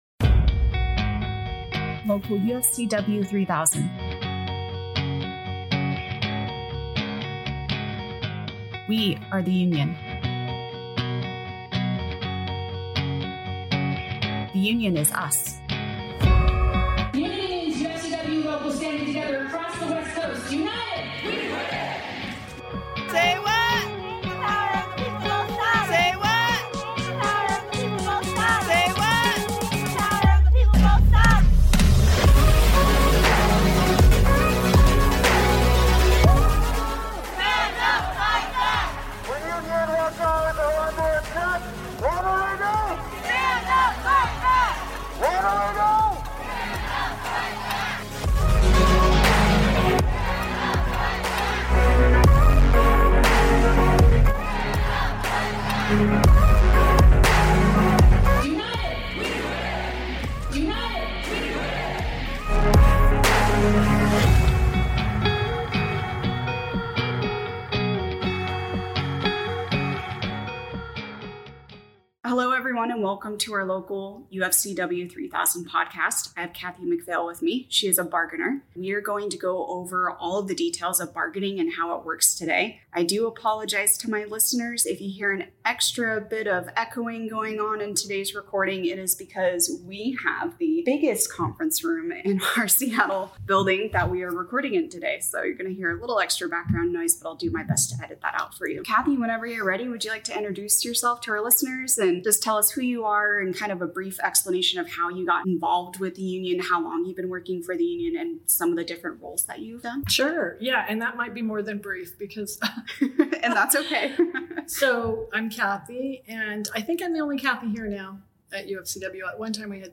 Hear directly from these striking nurses about what's at stake in their fight for a fair contract that respects their dedication and supports safe patient care for their community.